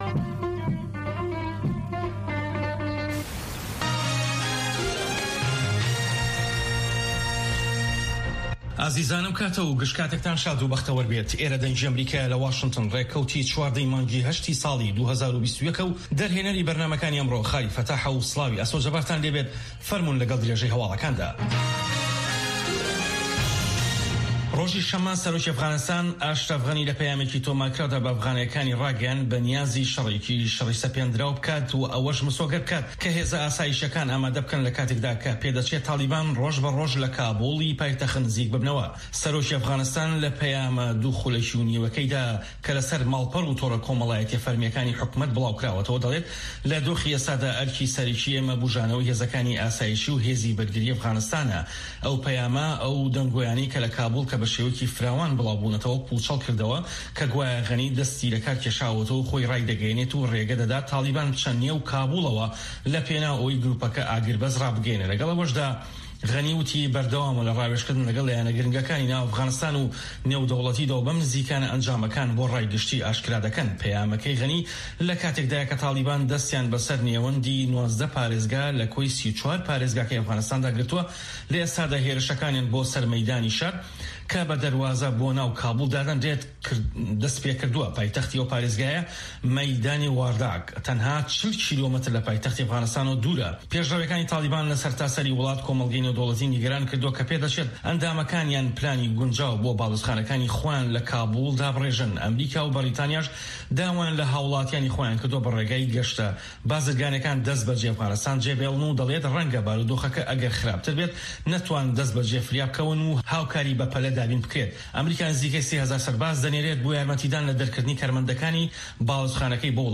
هەواڵەکانی 1 ی پاش نیوەڕۆ
هەواڵە جیهانیـیەکان لە دەنگی ئەمەریکا